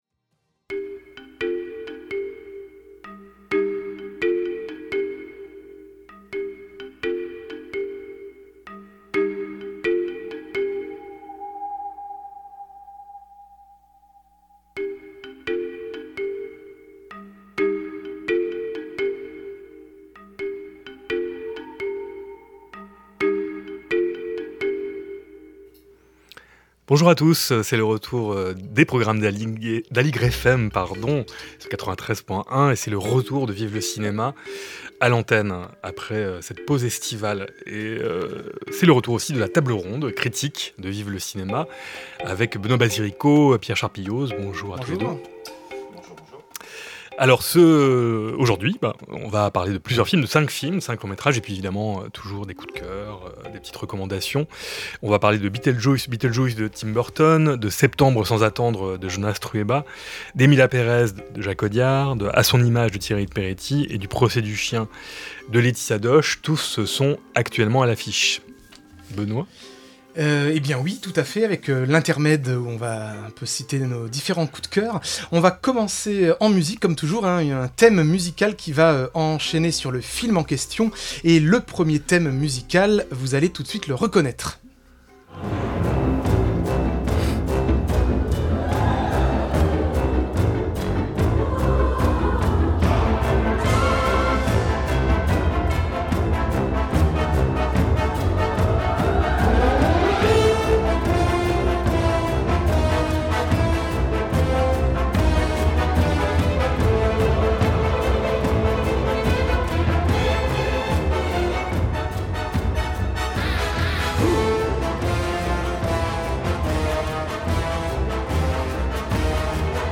Table ronde critique autour des films :